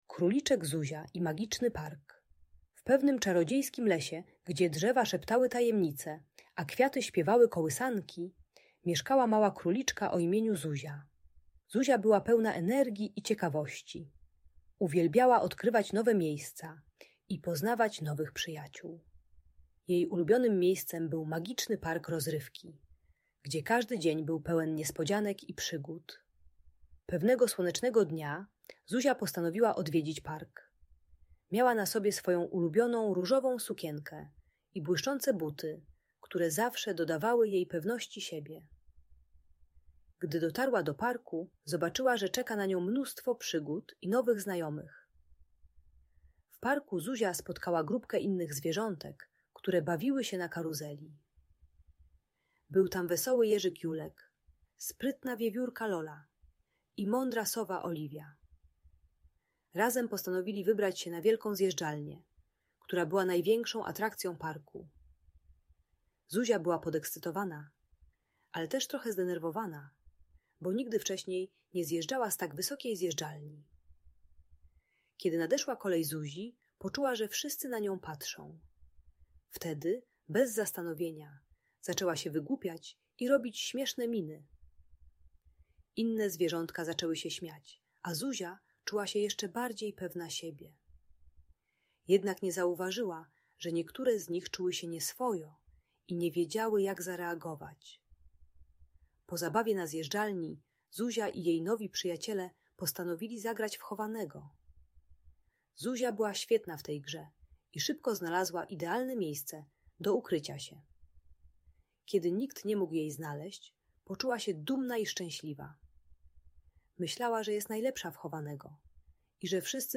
Historia Króliczka Zuzi i Magicznego Parku - Audiobajka